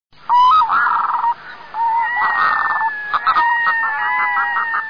Краснозобая гагара
Red-throated Diver (англ.), Red-throated Loon (амер.)
Голос краснозобой гагары очень звучен, набор криков необычайно широк. В полете можно слышать хрипловатое убыстряющееся “га... га... га... гарраа”, несколько более мелодичное, чем у чернозобой гагары, но сходное по построению, или одиночные отрывистые крики “гак” или “хак”.
Помимо этого, часто можно слышать мяукающие, стонущие или напоминающие хохот звуки, по тембру близкие к человеческому голосу.
gavia_stellata.mp3